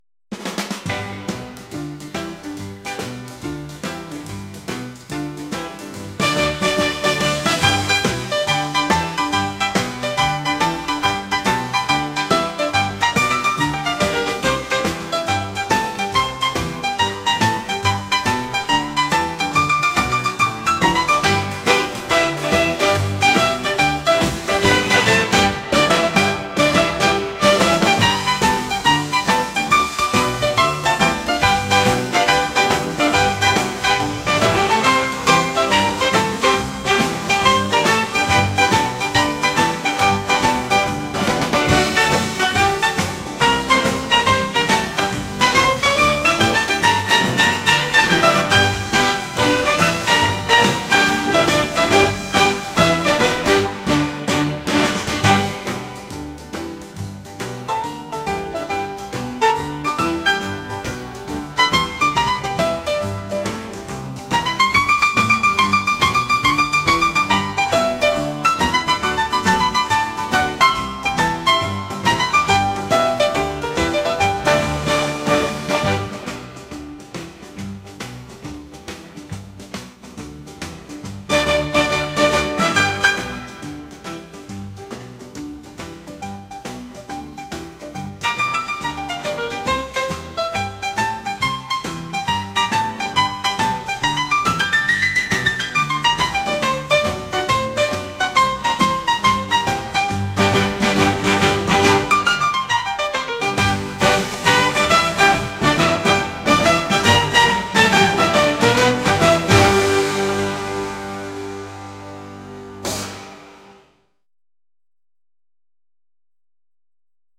soul & rnb | pop | lounge